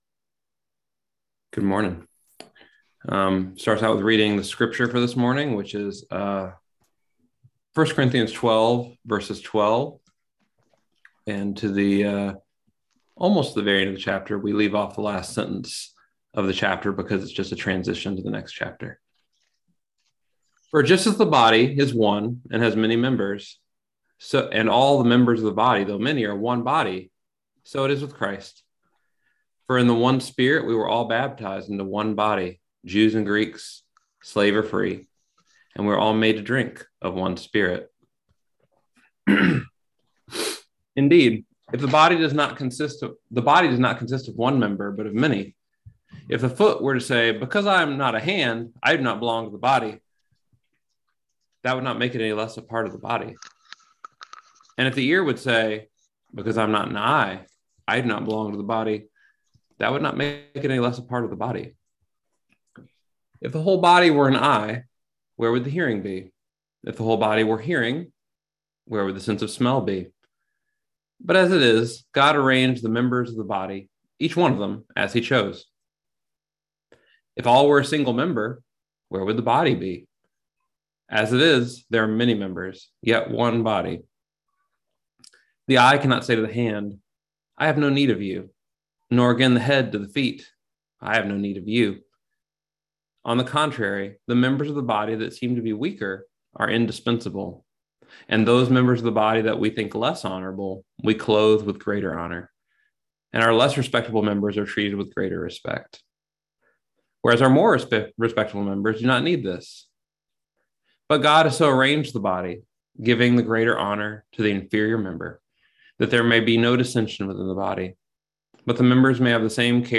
Message for January 23, 2022
Listen to the most recent message from Sunday worship at Berkeley Friends Church, “Being the Body.”